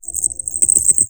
BraceletChime.wav